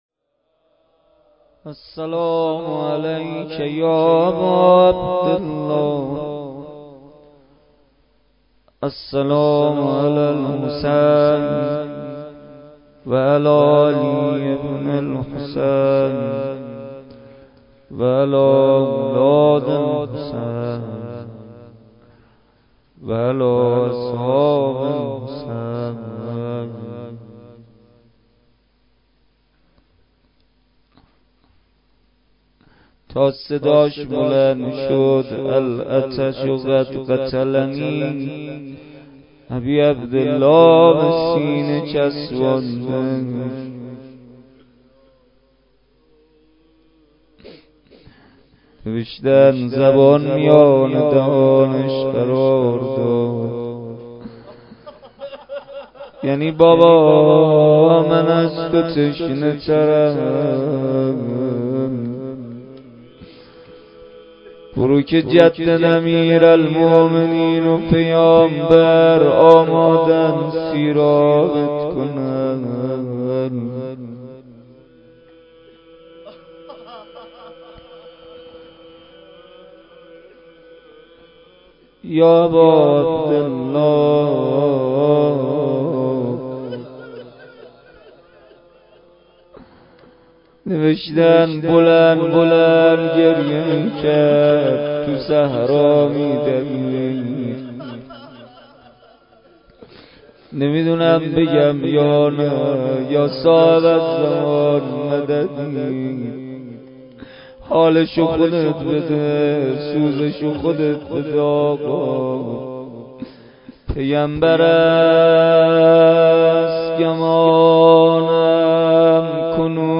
مداحی
در ولنجک – بلوار دانشجو – کهف الشهداء برگزار گردید.
کد خبر : ۵۷۱۷۸ عقیق:صوت این جلسه را بشنوید .دعا و مناجات روضه لینک کپی شد گزارش خطا پسندها 0 اشتراک گذاری فیسبوک سروش واتس‌اپ لینکدین توییتر تلگرام اشتراک گذاری فیسبوک سروش واتس‌اپ لینکدین توییتر تلگرام